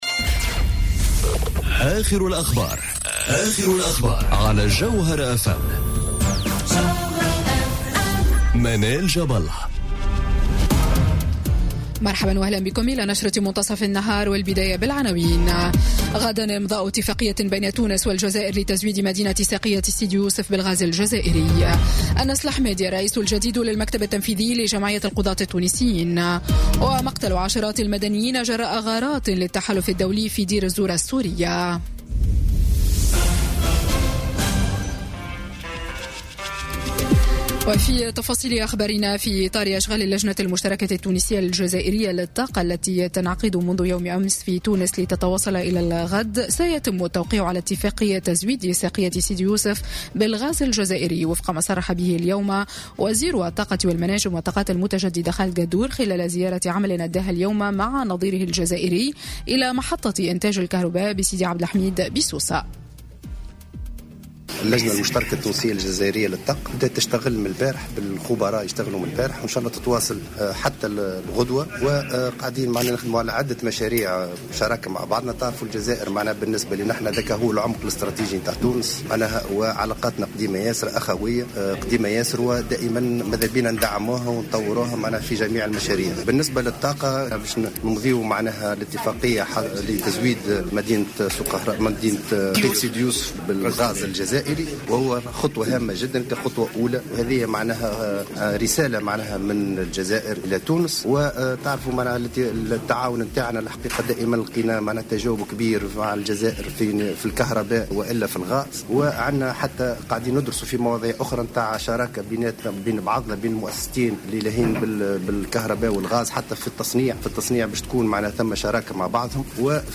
نشرة أخبار منتصف النهار ليوم الإثنين 26 فيفري 2018